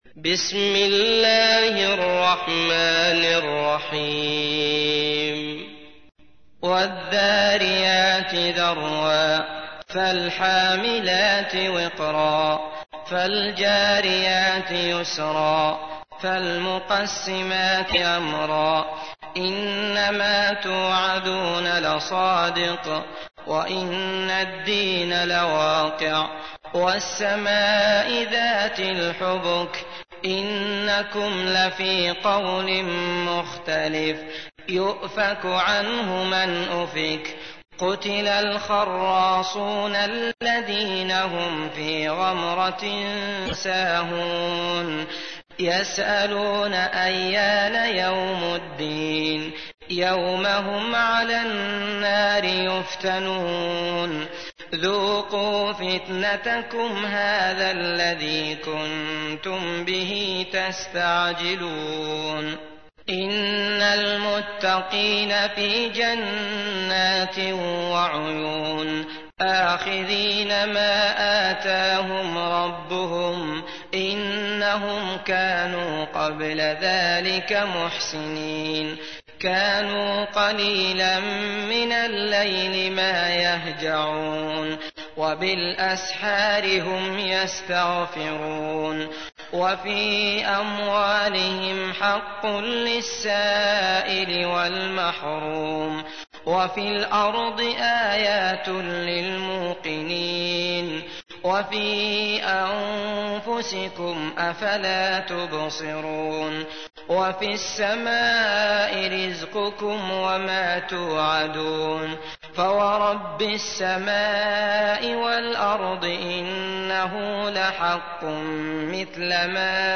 تحميل : 51. سورة الذاريات / القارئ عبد الله المطرود / القرآن الكريم / موقع يا حسين